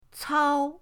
cao1.mp3